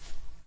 Demon Blade Sound FX " FX 002 footstep grass r
描述：草表面上的唯一脚步